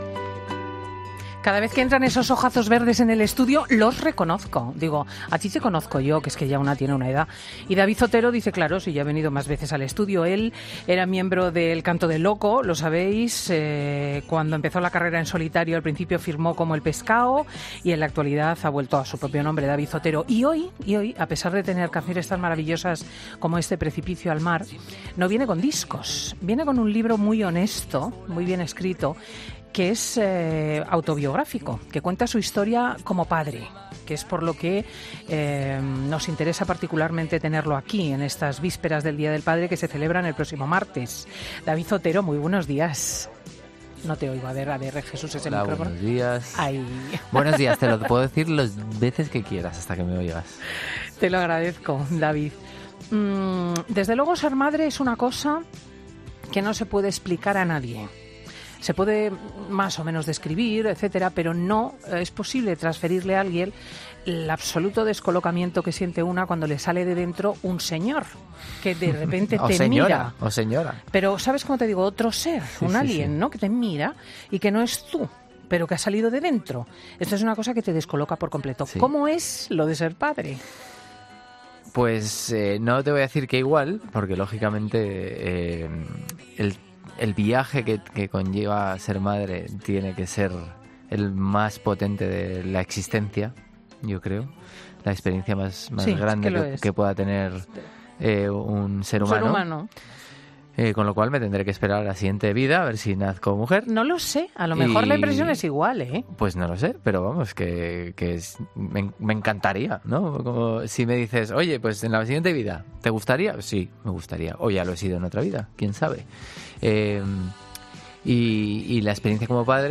David Otero presenta en 'Fin de Semana' su libro 'Precipicio al mar'